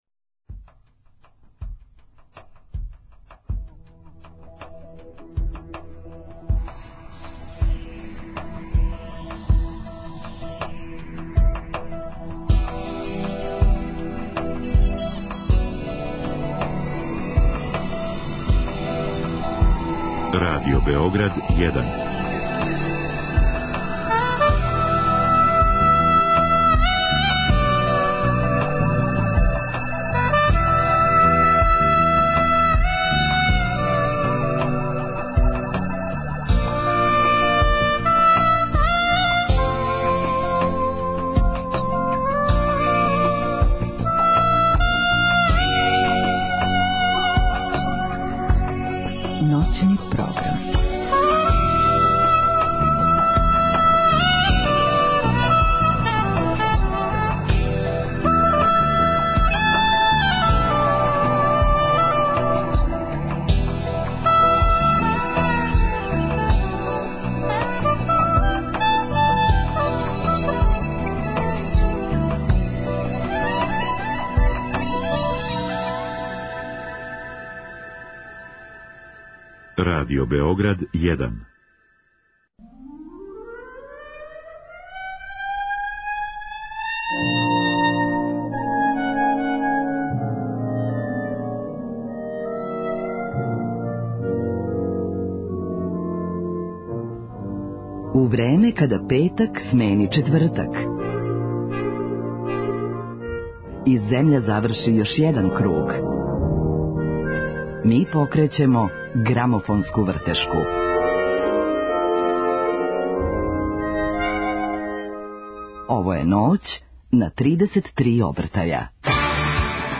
Гост Ноћи на 33 обртаја биће глумац Иван Босиљчић, чији ћемо музички избор слушати у првом сату. Разговараћемо о његовим глумачким почецима, каријери, љубави према музици, као и о његовом предсетојећем концерту у Мадленијануму.